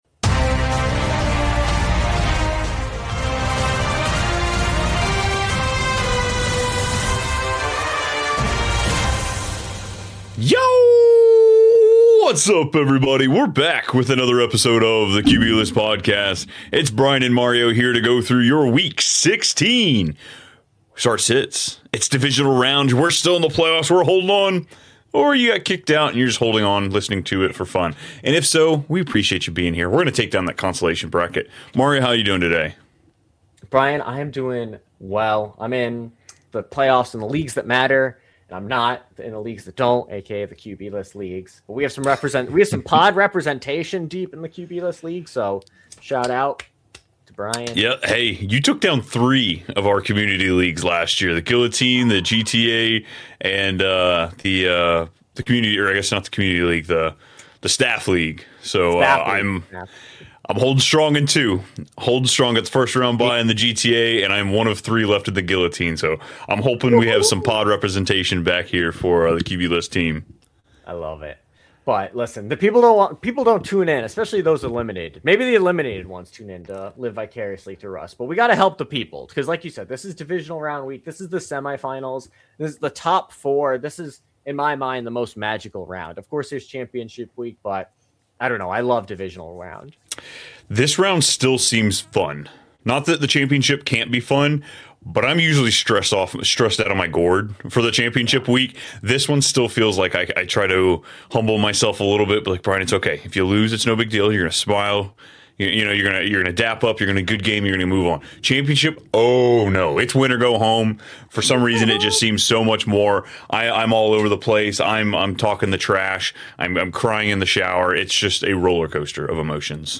Today, we go LIVE to break down the Whittingham-to-Michigan buzz, why his name is gaining traction, and how he would fit…